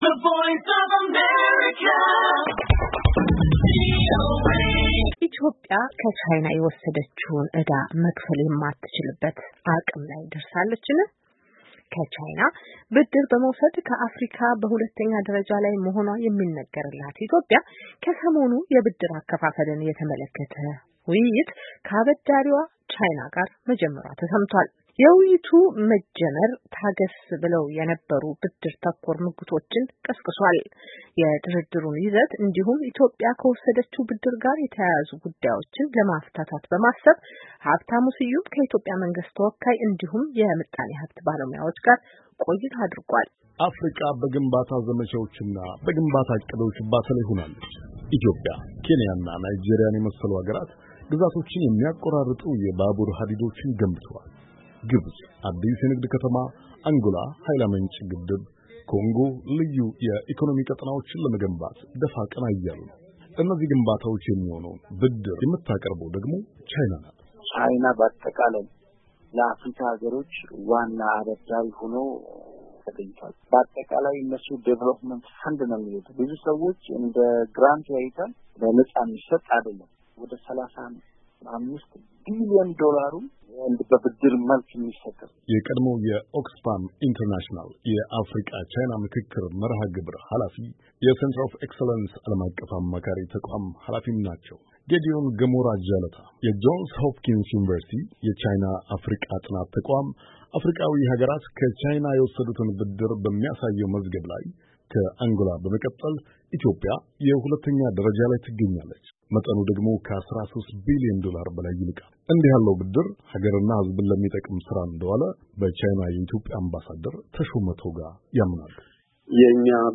ከኢትዮጵያ መንግስት ተወካይ እንዲሁም የምጣኔ ሀብት ባለሙያዎች ጋር ቆይታ አድርጓል፡፡